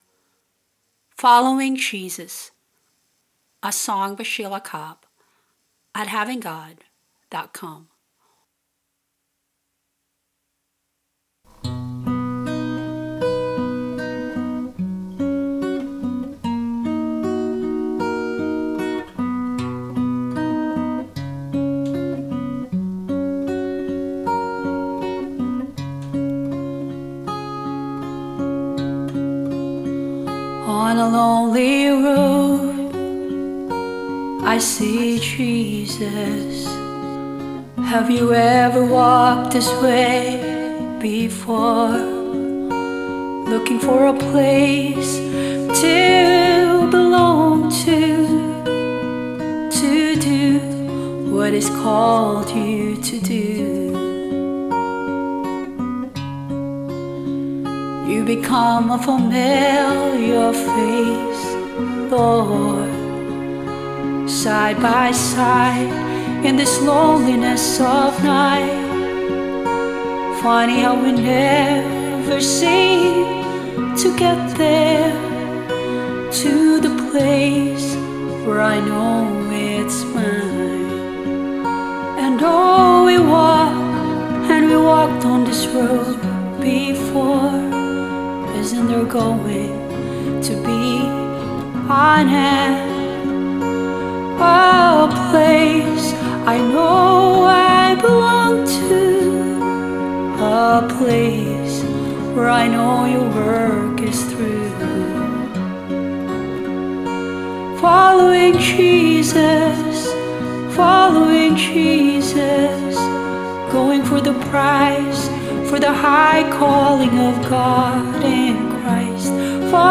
Vocals, guitar and bass
Strings and keyboard